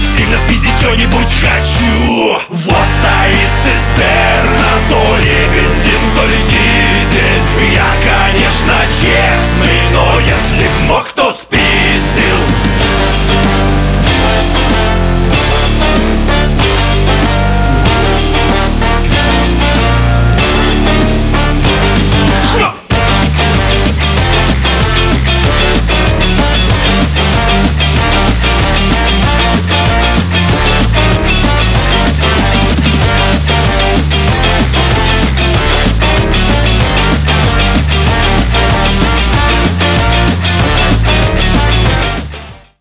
• Качество: 320, Stereo
мужской голос
веселые
смешные